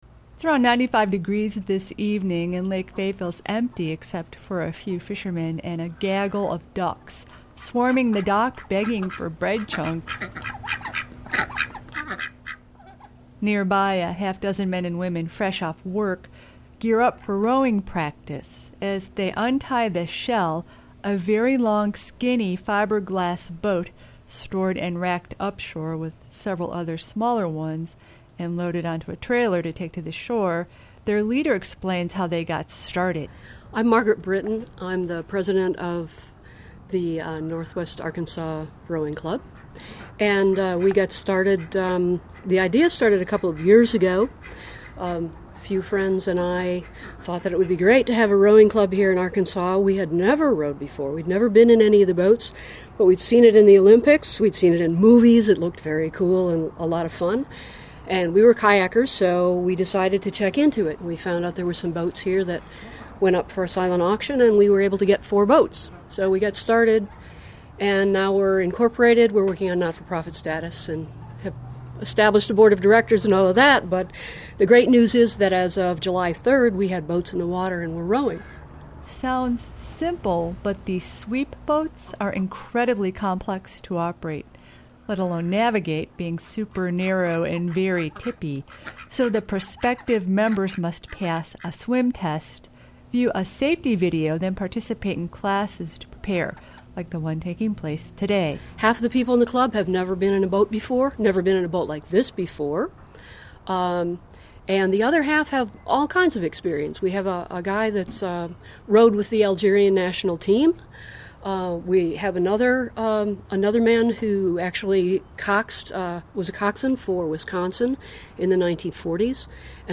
Rowing Club of Northwest Arkansas NWA_Rowing_Club.mp3 We follow the new Northwest Arkansas Rowing Club as they conduct drills on Lake Fayetteville. The club is open to new members, both novice and expert.